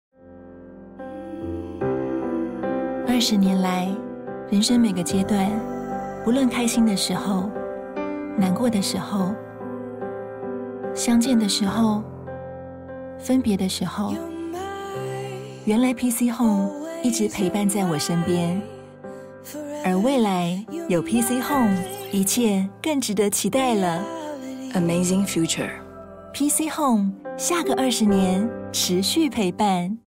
國語配音 女性配音員
她在廣告中憑藉真摯而又帶有親和力的聲音，成功塑造出鮮明的品牌形象，是業界廣告配音的熱門選擇。